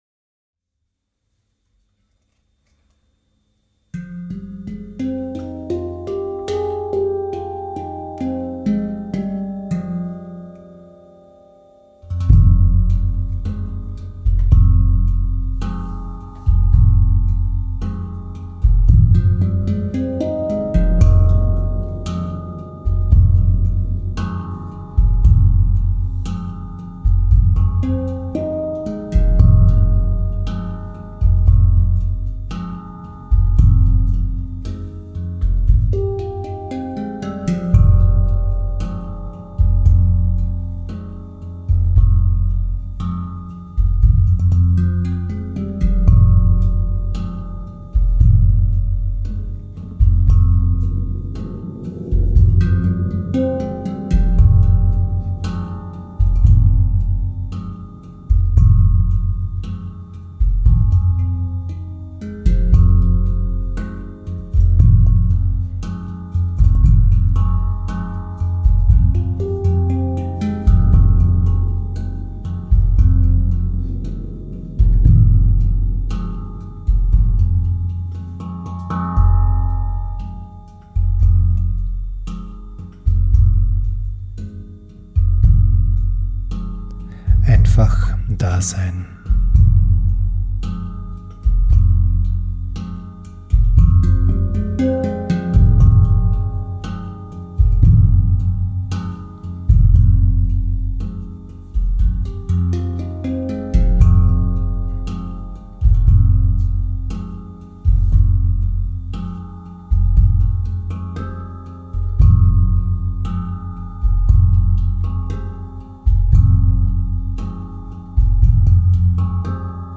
Metrisches Klang-Focusing
Angeregt durch meine Arbeit über das Metrum beschäftige ich mich mit der Frage, wie ich während einer gesprochenen Focusinganleitung mit metrisch-rhythmischen Wiederholungen diverser Klanginstrumente (Handpan, Gong, Trommel …) eine sichere und gleichzeitig vertiefende Atmosphäre schaffen kann, damit ein hilfreicher Focusingprozess in Gang kommt.
Zu empfehlen wäre die Verwendung von Kopfhörern, damit der Klang der Instrumente und die Stimme in einer guten Klangqualität hörbar werden.